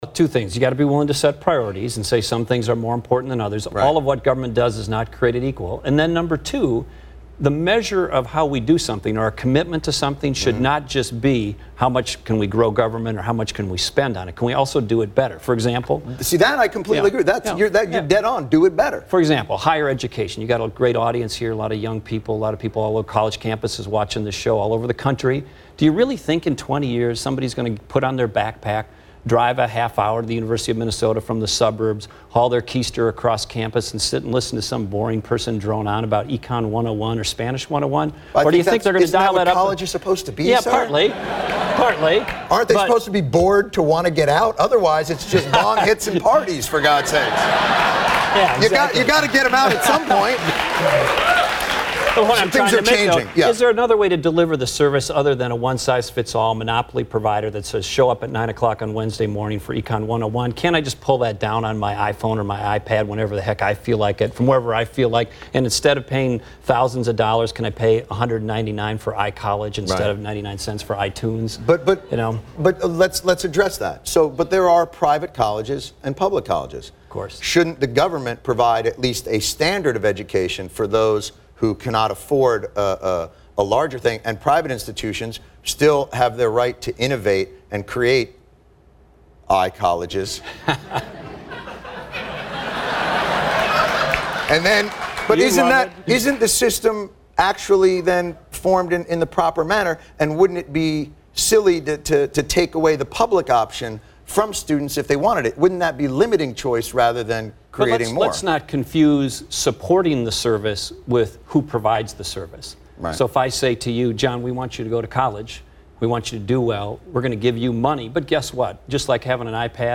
On 10 June 2010, Minnesota Governor Tim Pawlenty appeared on Jon Stewart’s “The Daily Show.” One of the topics they touched on was online learning in higher education. The audio clip below is an excerpt from the TV show.